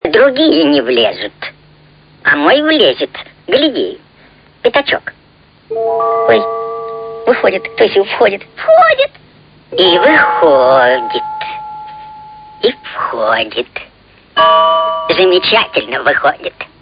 Голос ослика Иа из Винни Пуха